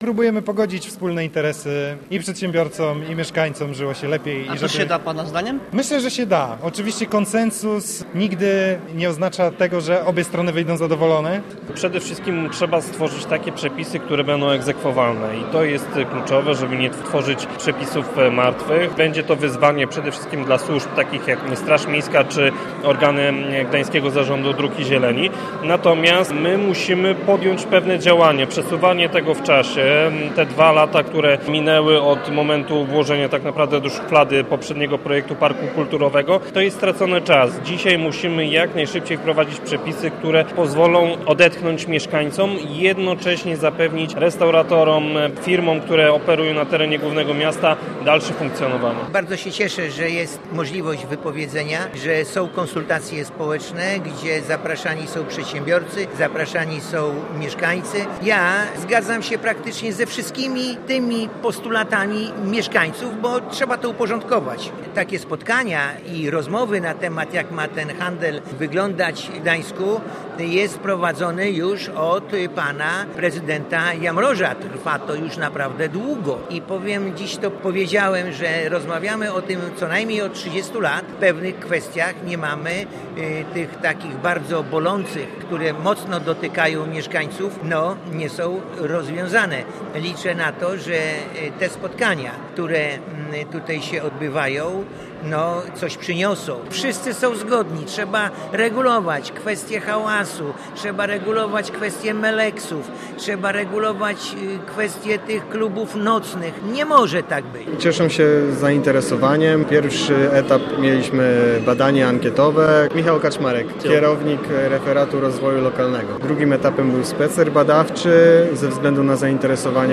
Posłuchaj materiału reportera: https